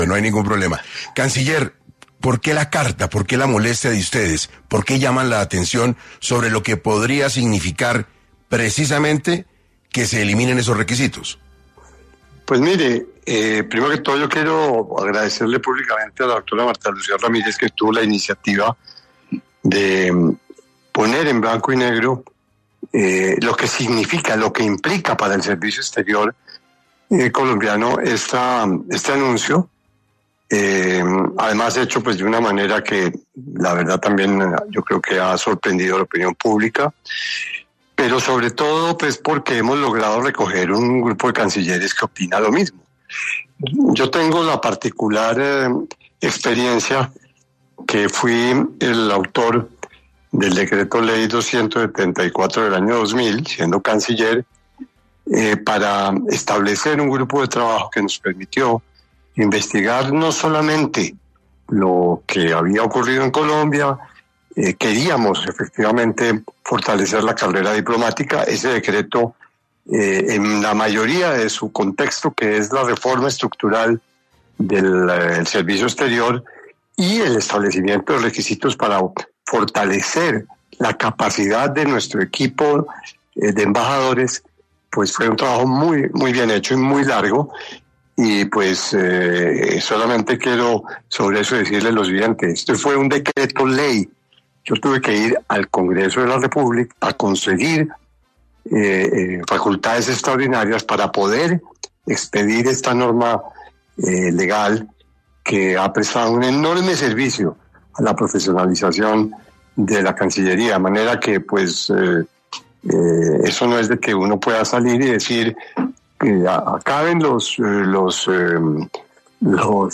En entrevista con 6AM de Caracol Radio, Guillermo Fernández de Soto, excanciller y autor del decreto ley 274 del año 2000, que establece los requisitos para el servicio exterior, explicó que el decreto fue el resultado de un trabajo muy bien hecho y largo, que buscaba fortalecer la carrera diplomática.